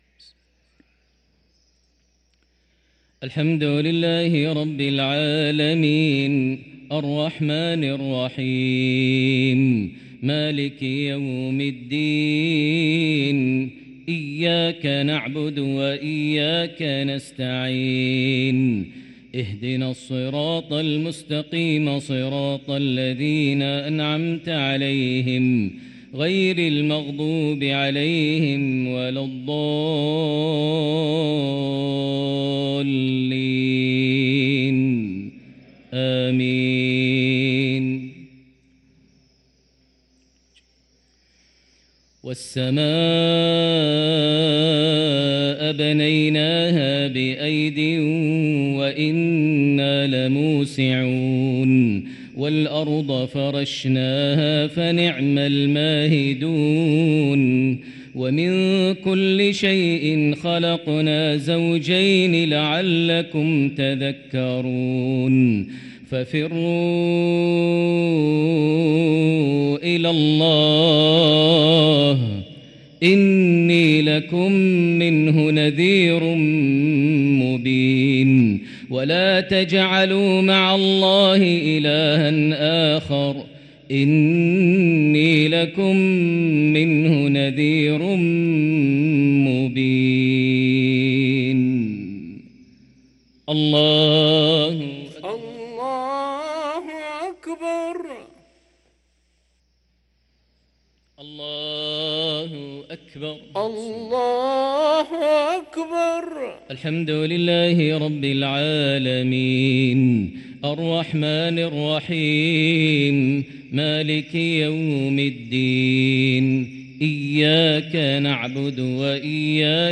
صلاة المغرب للقارئ ماهر المعيقلي 11 جمادي الآخر 1445 هـ
تِلَاوَات الْحَرَمَيْن .